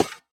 Minecraft Version Minecraft Version 1.21.5 Latest Release | Latest Snapshot 1.21.5 / assets / minecraft / sounds / block / trial_spawner / step4.ogg Compare With Compare With Latest Release | Latest Snapshot
step4.ogg